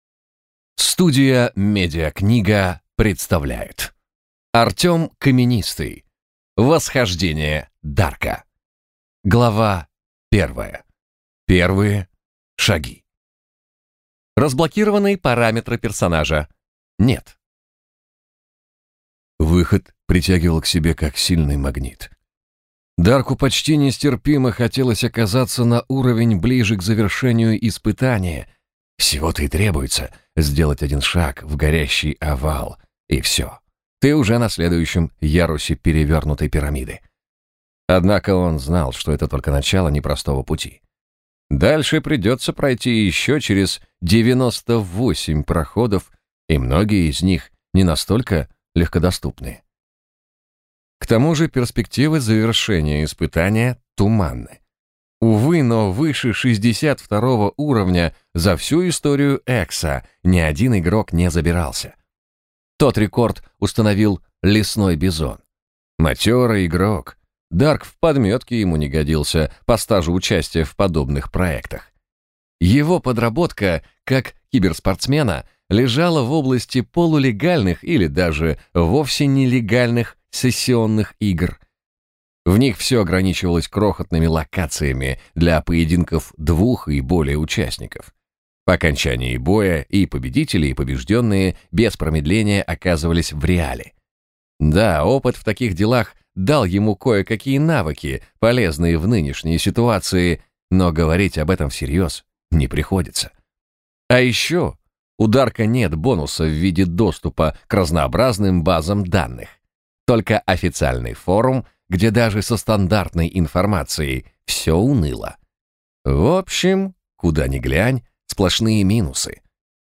Аудиокнига Восхождение Дарка - купить, скачать и слушать онлайн | КнигоПоиск